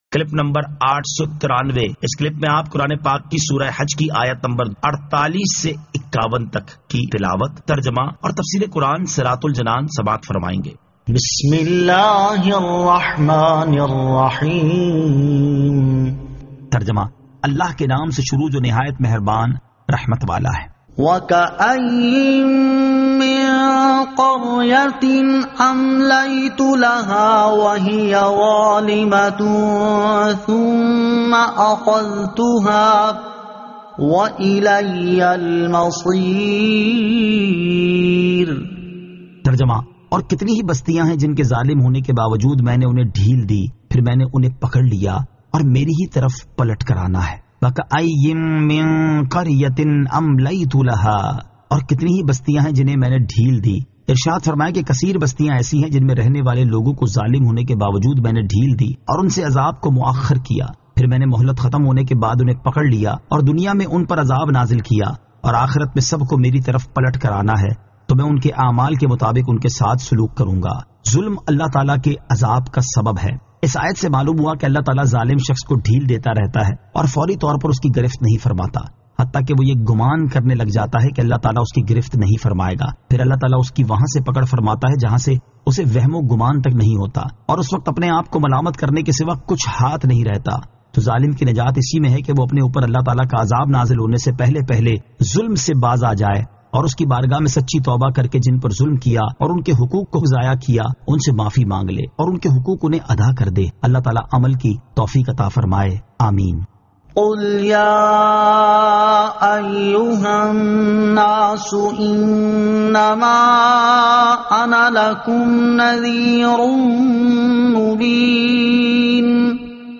Surah Al-Hajj 48 To 51 Tilawat , Tarjama , Tafseer